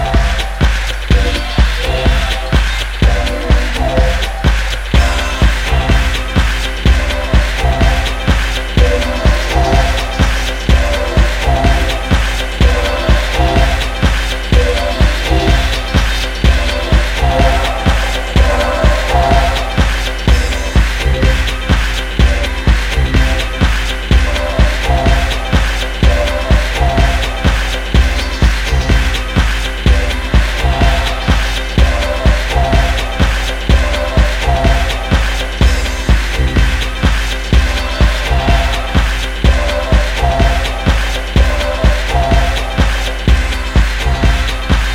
とありましたが、確かにそんなシチュエーションがバッチリハマるドラッギーな強力ウェポン！